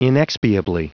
Prononciation du mot inexpiably en anglais (fichier audio)
inexpiably.wav